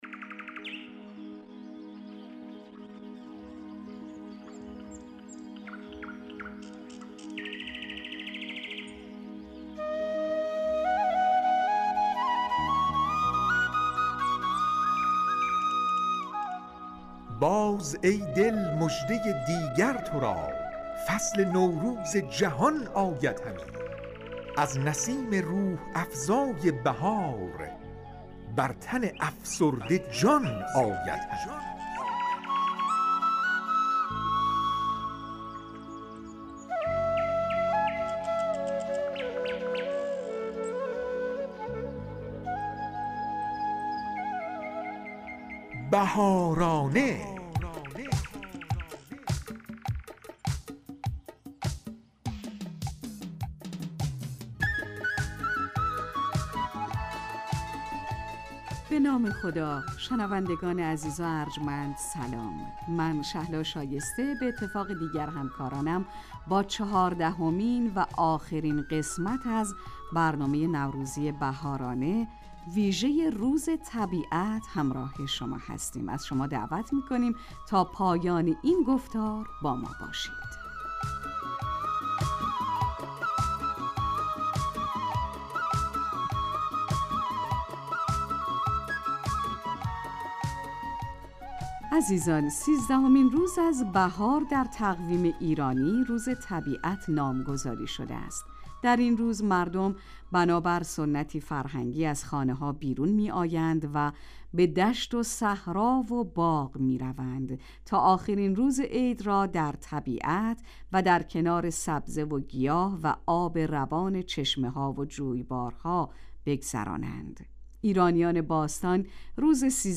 "بهارانه" ویژه برنامه نوروزی رادیو تاجیکی صدای خراسان است که به مناسبت ایام نوروز در این رادیو به مدت 30 دقیقه تهیه و پخش می شود.